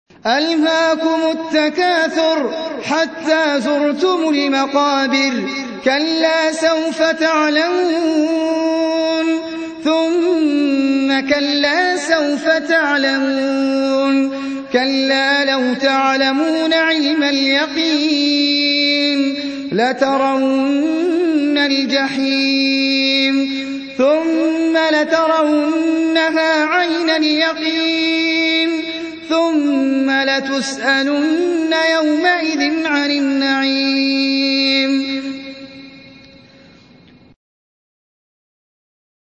Surah Sequence تتابع السورة Download Surah حمّل السورة Reciting Murattalah Audio for 102. Surah At-Tak�thur سورة التكاثر N.B *Surah Includes Al-Basmalah Reciters Sequents تتابع التلاوات Reciters Repeats تكرار التلاوات